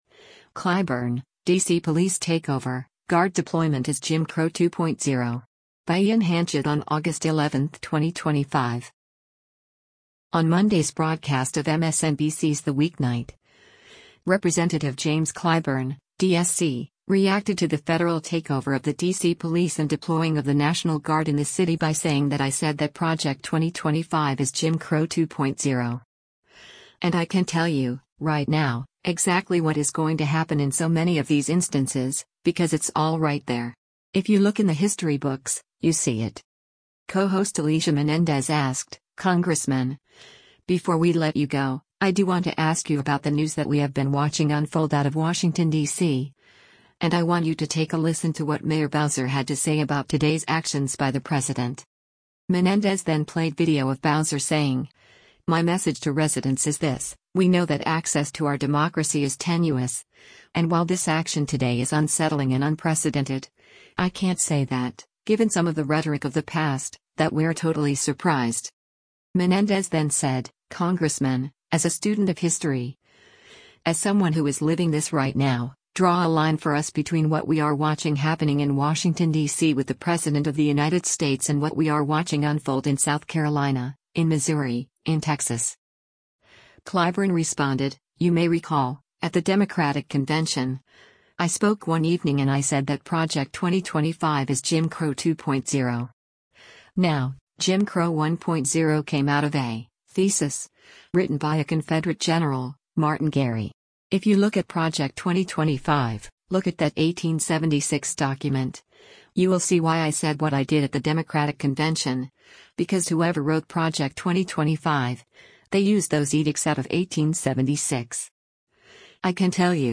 On Monday’s broadcast of MSNBC’s “The Weeknight,” Rep. James Clyburn (D-SC) reacted to the federal takeover of the D.C. police and deploying of the National Guard in the city by saying that “I said that Project 2025 is Jim Crow 2.0.” And “I can tell you, right now, exactly what is going to happen in so many of these instances, because it’s all right there. If you look in the history books, you see it.”